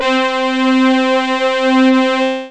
OLD BRASS.wav